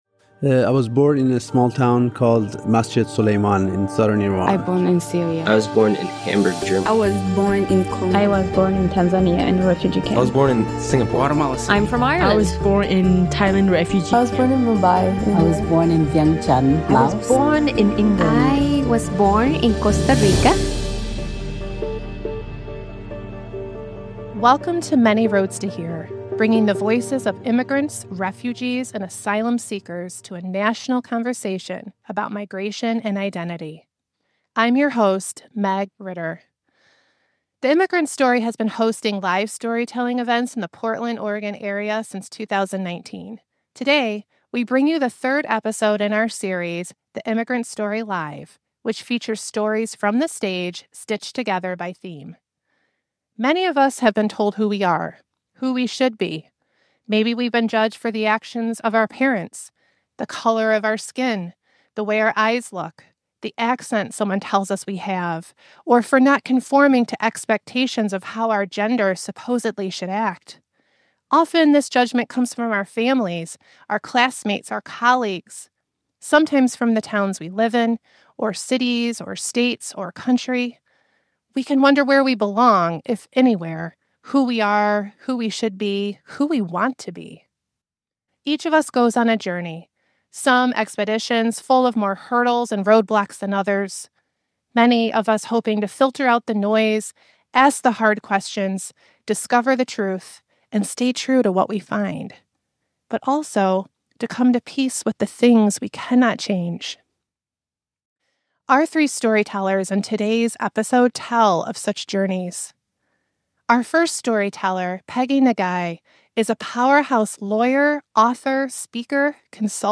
Our three storytellers in today’s episode tell of such journeys.
All stories were part of I’m an American Live , a series of storytelling events showcasing Asian American voices, staged at the Patricia Reser Center for the Performing Arts in Beaverton, Oregon.